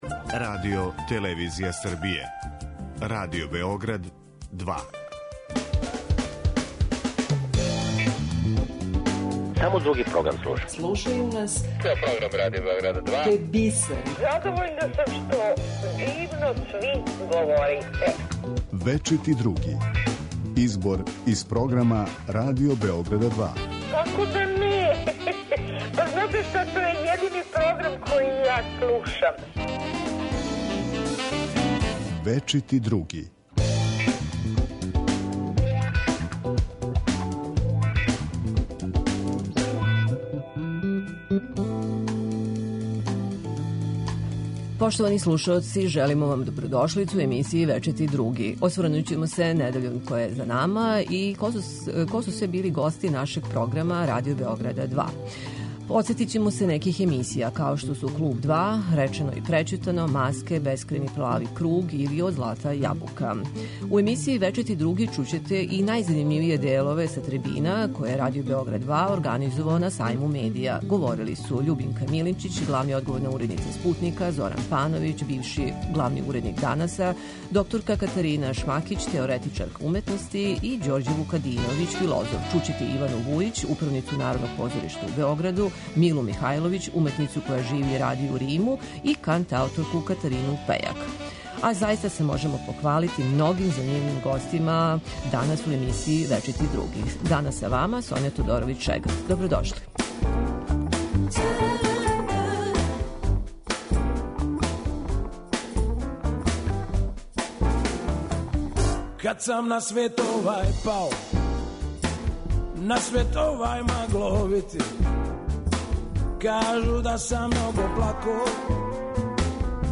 У емисији Вечити други чућете најзанимљивије делове са трибина које је Радио Београд 2 организовао на Сајму медија